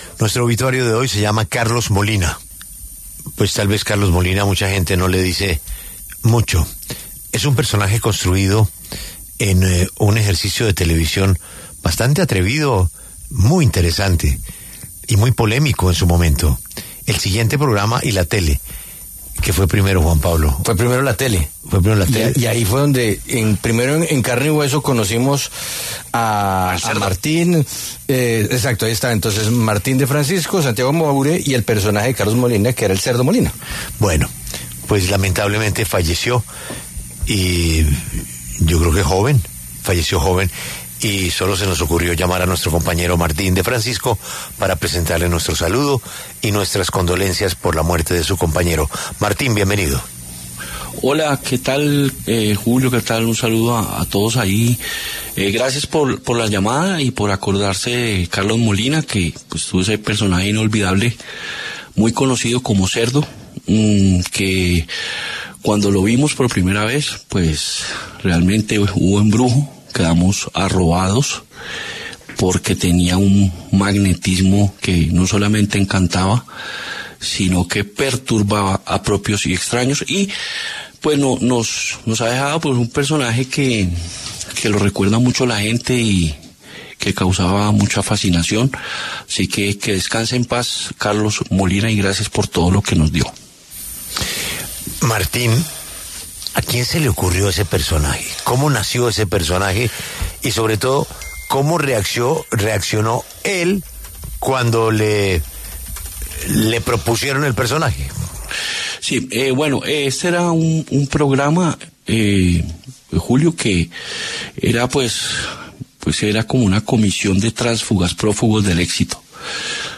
Martín De Francisco recordó en los micrófonos de La W a Carlos ‘El Cerdo’ Molina, su compañero de escenario en La Tele Letal.
De Francisco, quien también presenta el programa de deportes Peláez De Francisco en La W, pasó por los micrófonos de La W, con Julio Sánchez Cristo, para hablar sobre su compañero.